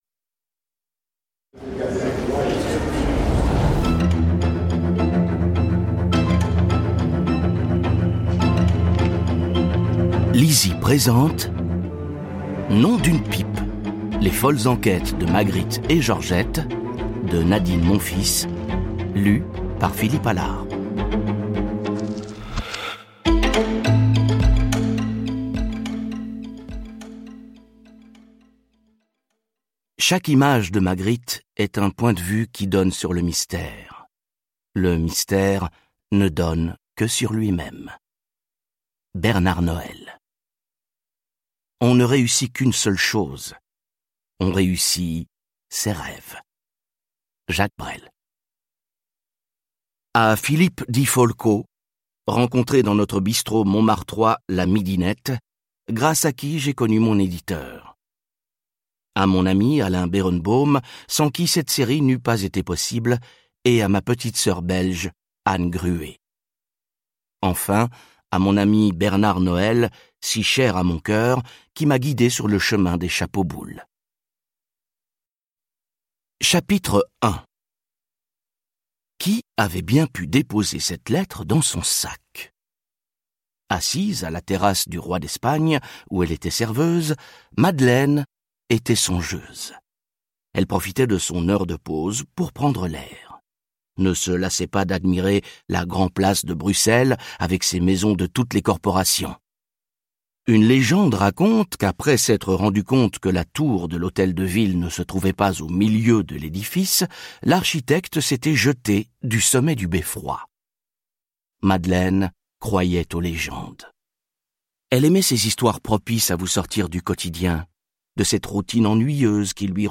Extrait gratuit - Les Folles enquêtes de Magritte et Georgette : Nom d'une pipe ! de Nadine MONFILS